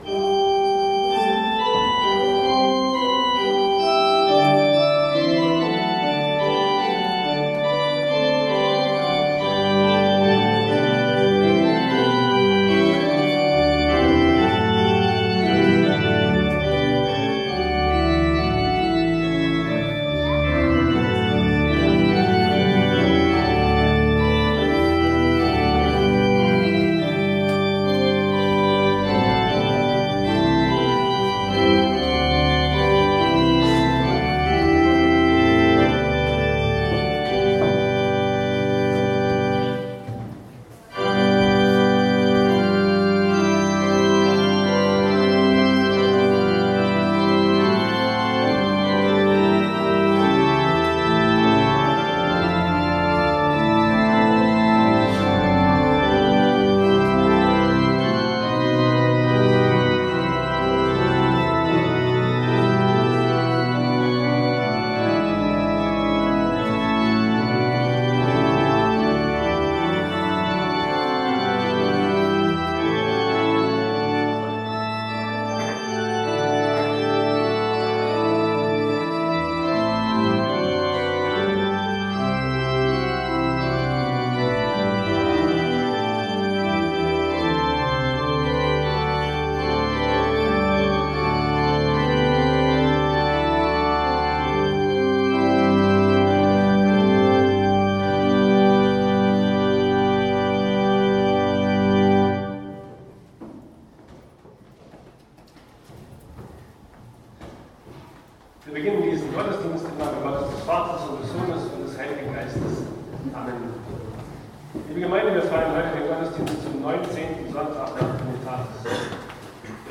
Predigten - Zionsgemeinde Hartenstein